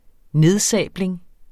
Udtale [ ˈneðˌsæˀbleŋ ]